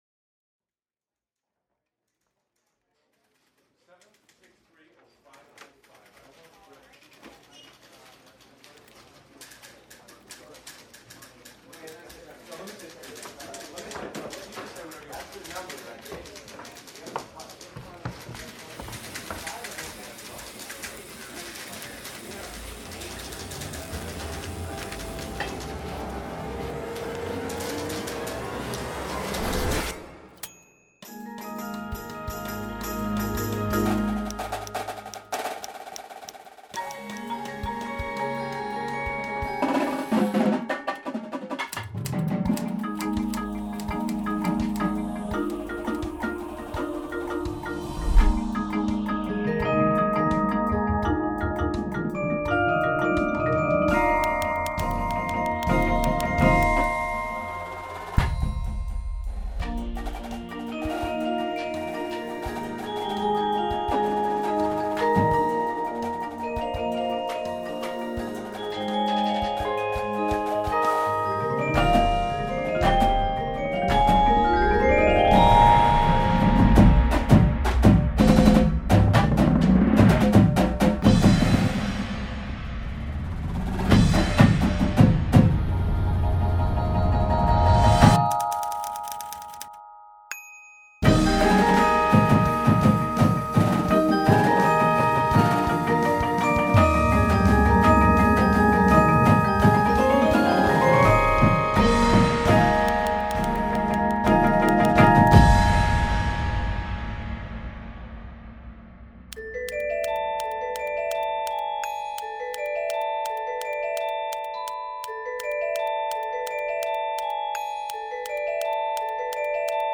• Marching Snare Drum
• Marching Tenors (4, 5, and 6 drum parts available)
• Marching Bass Drum (4 and 5 drum parts available)
Front Ensemble
• One synthesizer part (Mainstage patches included)
• Marimba 1/2
• Xylophone
• Glockenspiel
• Vibraphone 1/2
• Drumset
• Auxiliary Percussion